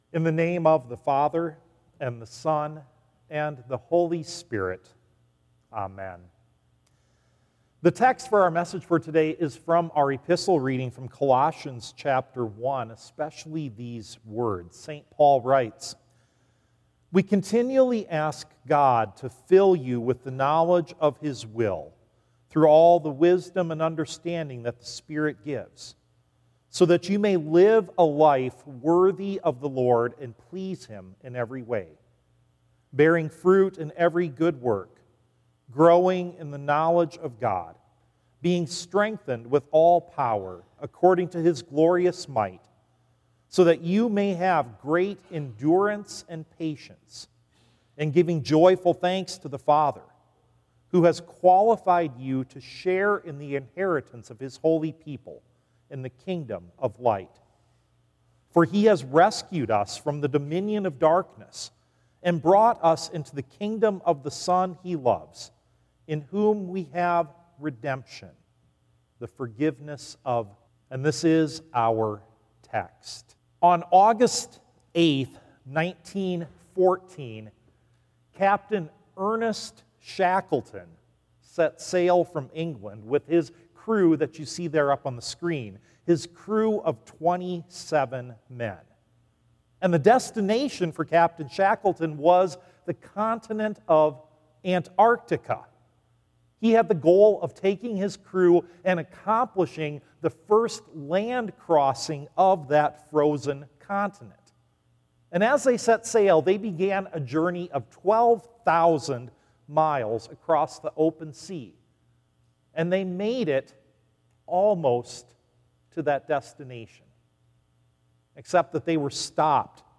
Zion_July_13_2025_sermon.mp3